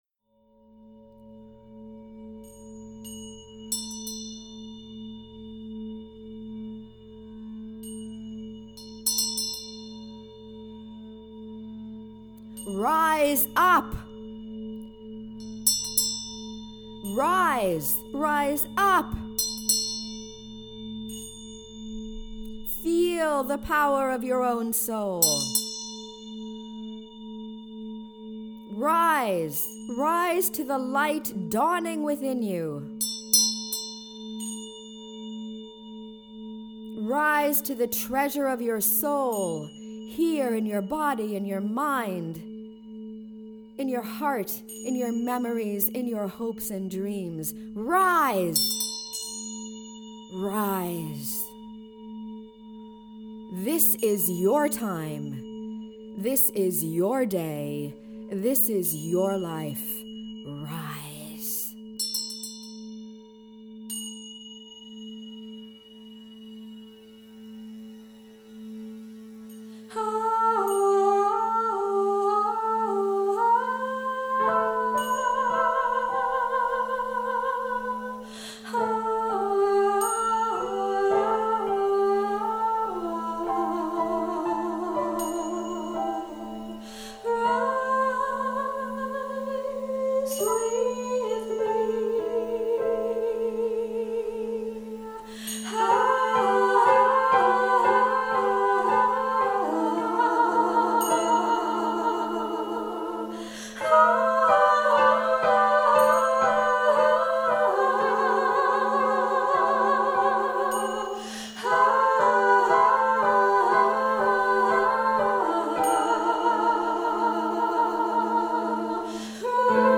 Credits Vocals, Tibetan Bell, and Tingshas
Piano and Soundscape